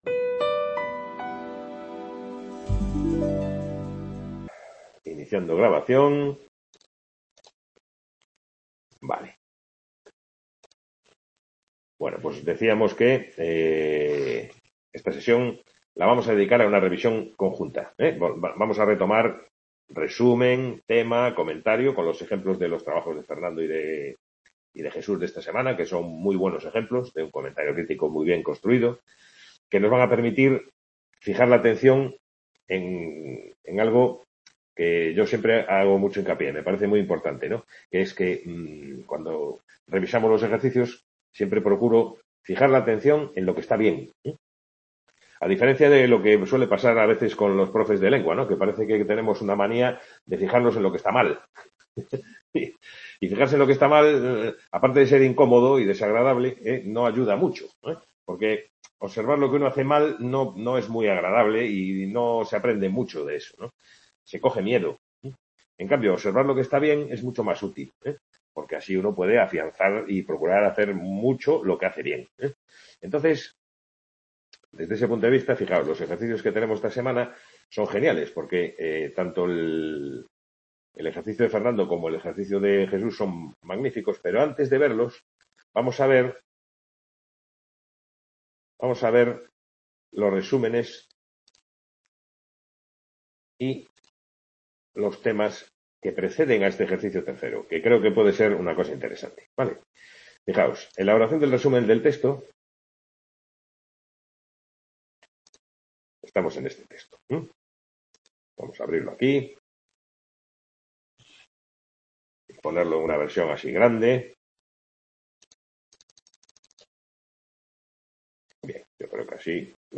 Sesión de tutoría (09/02/2022) Description Sesión de tutoría de la asignatura comentario de texto, dedicada a la revisión de ejercicios de comentario personal.